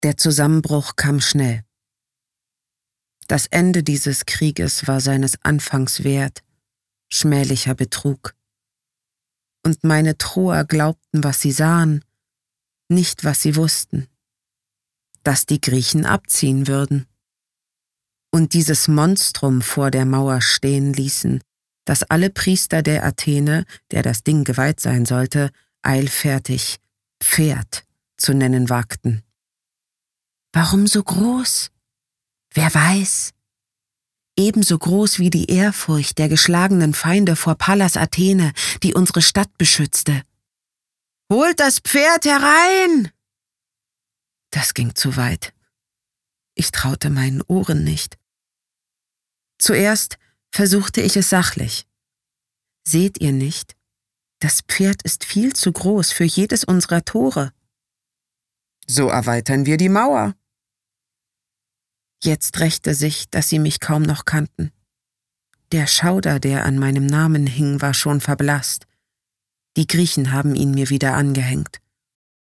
dunkel, sonor, souverän, sehr variabel
Tale (Erzählung)